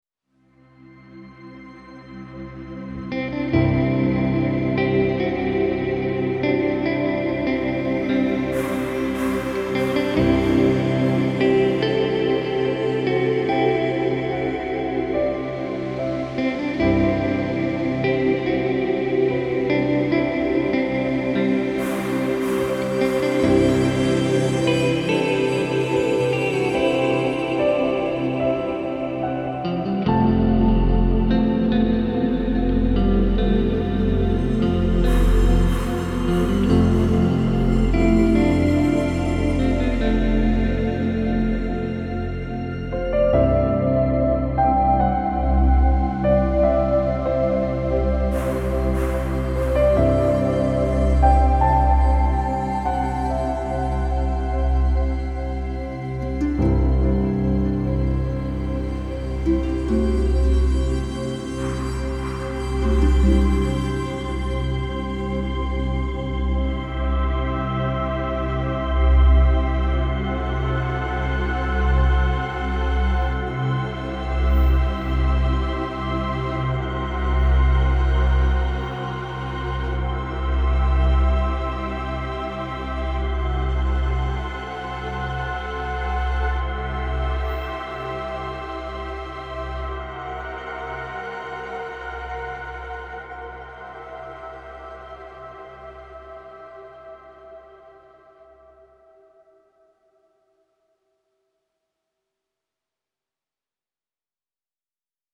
Genre : Film Soundtracks